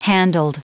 Prononciation du mot handled en anglais (fichier audio)
Prononciation du mot : handled